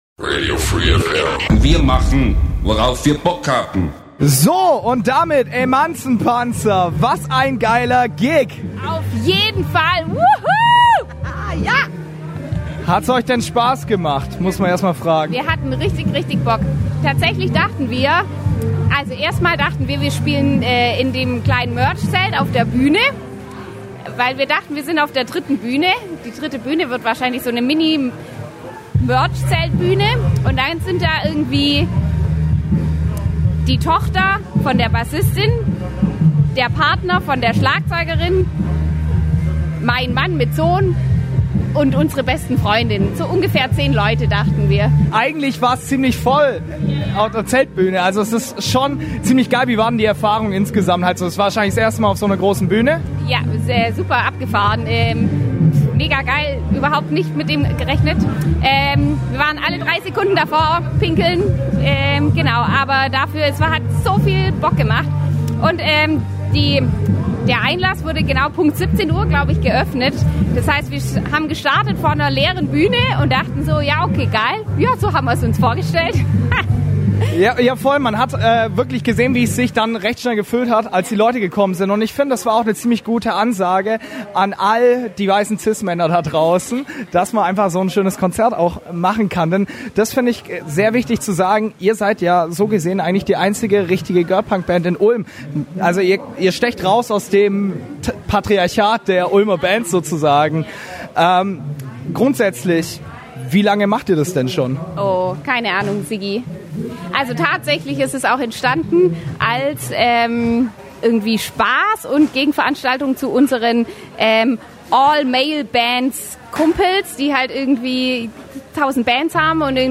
Aber lohnen würde es sich auf jeden Fall, denn Emanzenpanzer sind die einzige echte "Girl-Punk-Band" aus Ulm und machen ganz schön Alarm. Nach ihrem bisher größten Auftritt auf dem Obstwiesenfestival 2022 konnten wir der Band noch ein paar Worte entlocken.
Interview
Emanzenpanzer_OWF_Interview.mp3